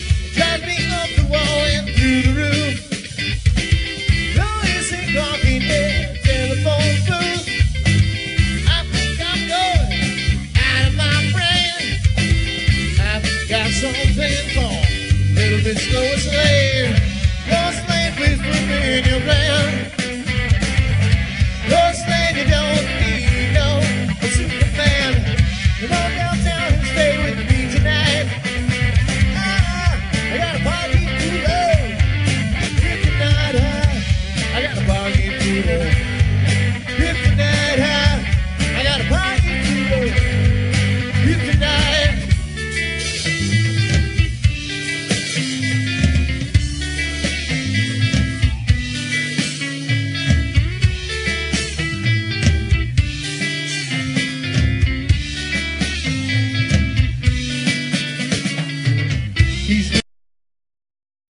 cover tunes